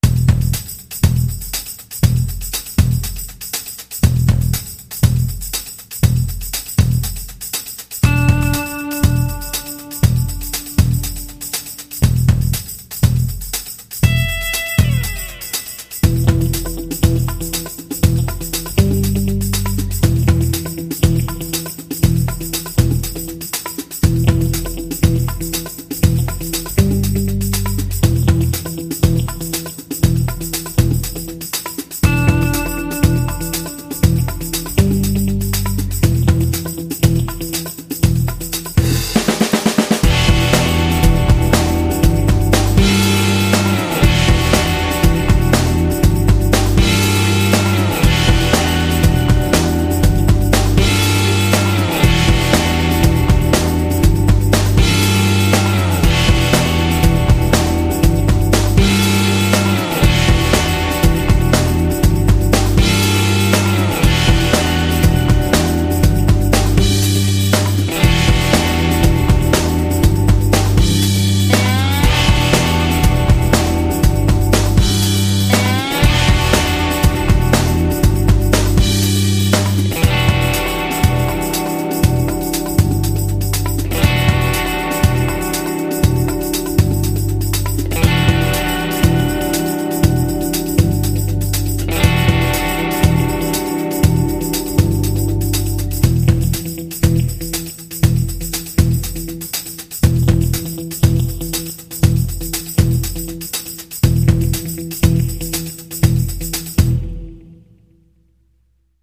это энергичная и мощная композиция в жанре поп-рок
С яркими гитарными рифами и захватывающим вокалом